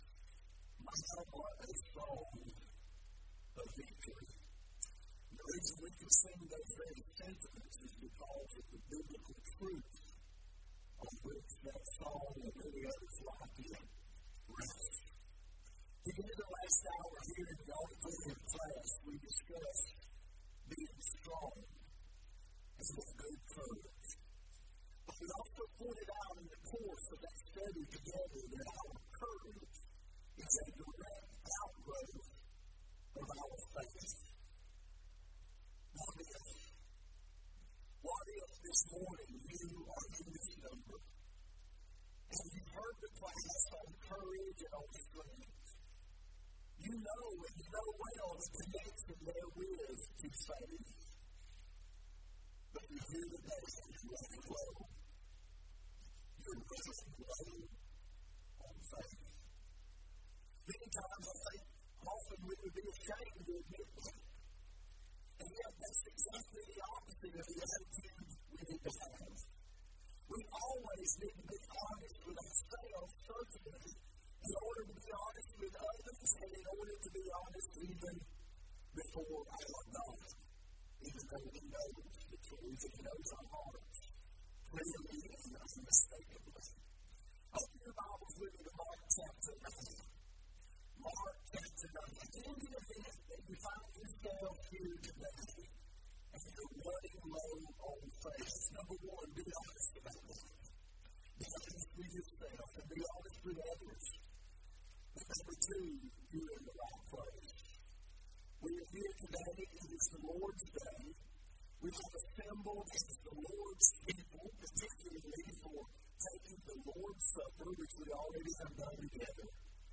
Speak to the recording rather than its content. Alternate File Link File Details: Series: Arise: Southwest Spiritual Growth Workshop Event: 7th Annual Arise: Southwest Spiritual Growth Workshop Theme/Title: Arise with Conviction!